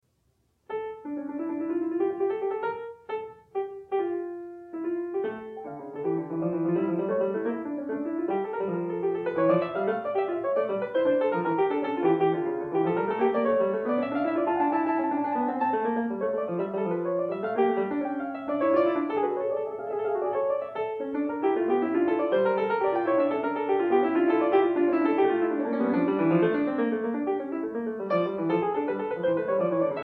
PLAYED ON STEINWAY